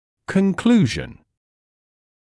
[kən’kluːʒn][кэн’клуːжн]заключение, вывод